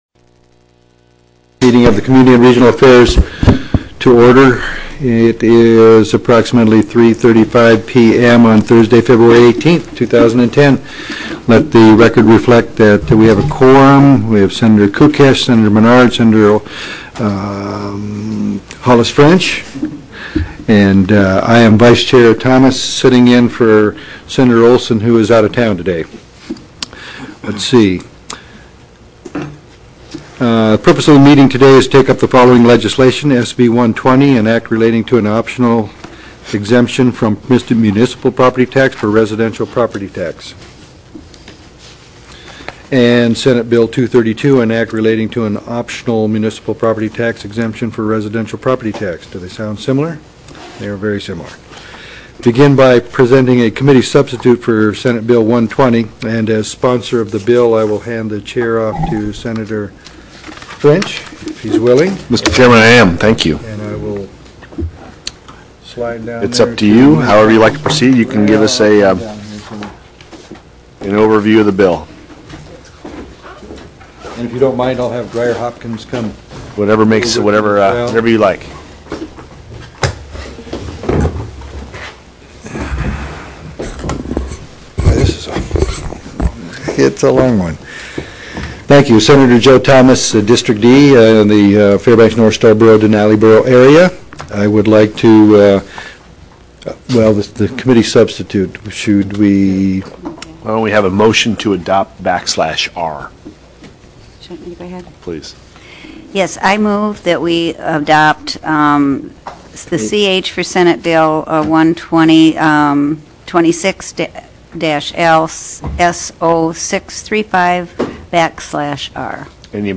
02/18/2010 03:30 PM Senate COMMUNITY & REGIONAL AFFAIRS